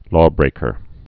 (lôbrākər)